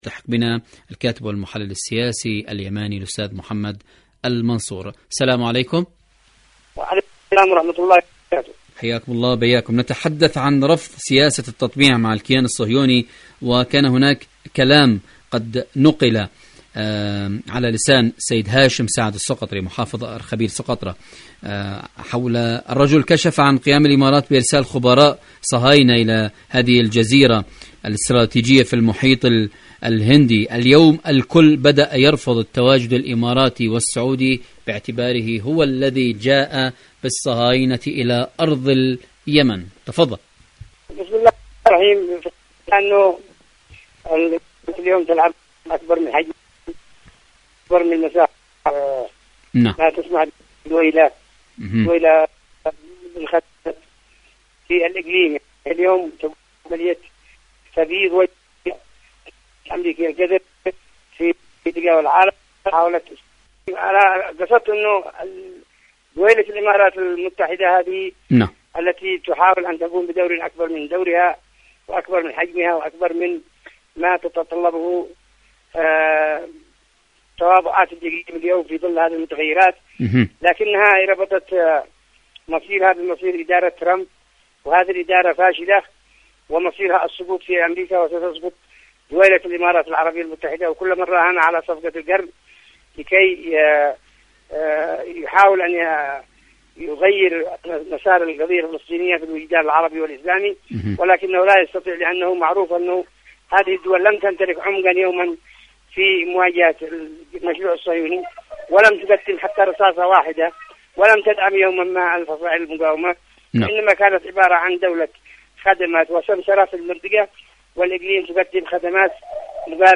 إذاعة طهران-اليمن التصدي والتحدي: مقابلة إذاعية